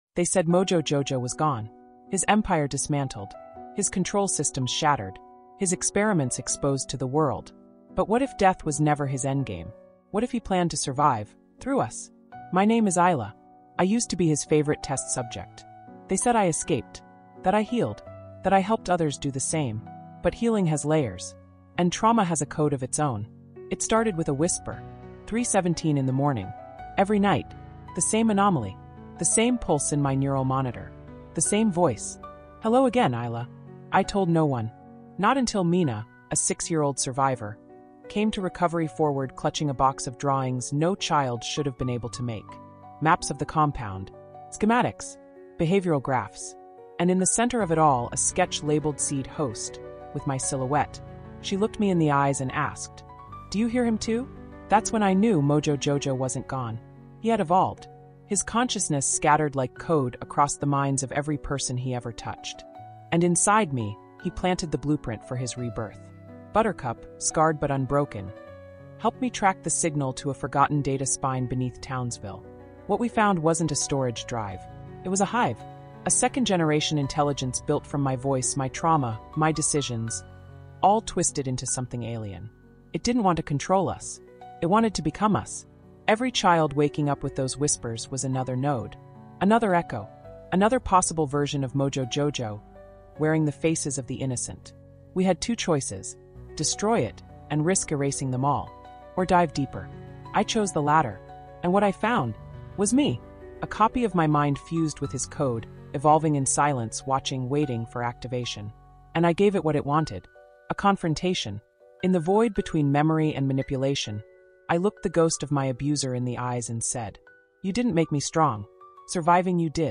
Whispers Behind the Code | A Mind Games Spin-Off | Audiobook insight